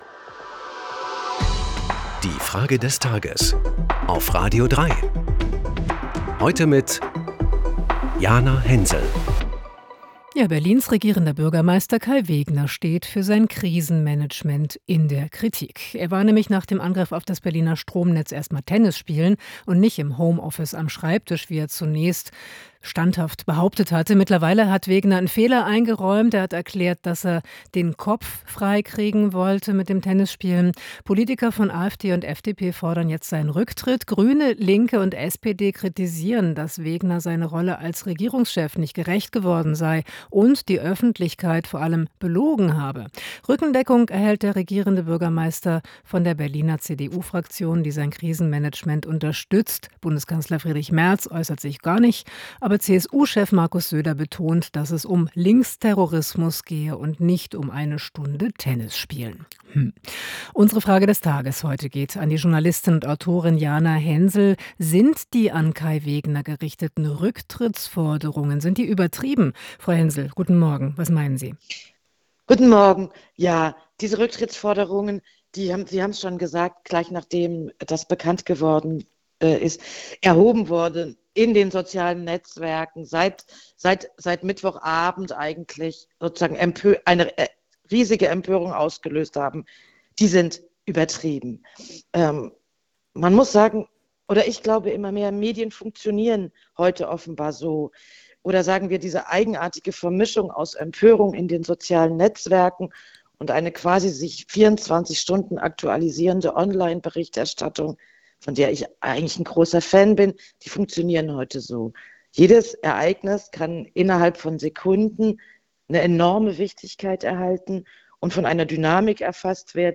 Journalistin und Autorin Jana Hensel.